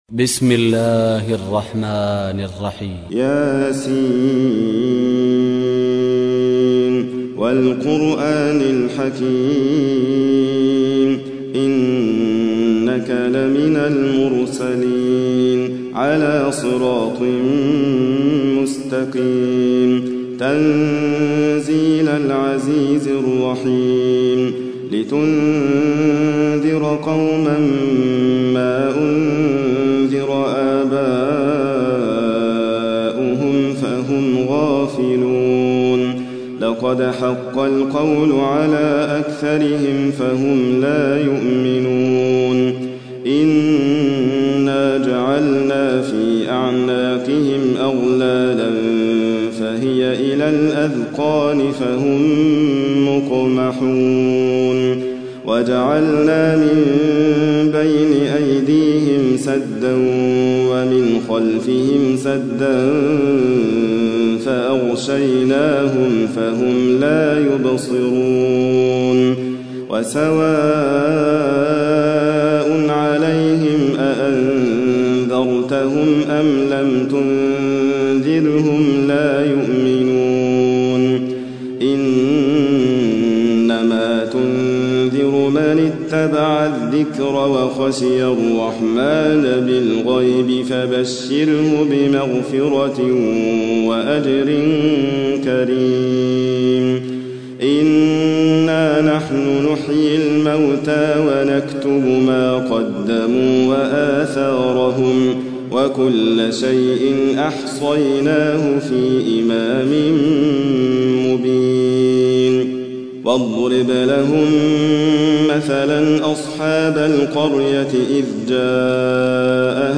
تحميل : 36. سورة يس / القارئ حاتم فريد الواعر / القرآن الكريم / موقع يا حسين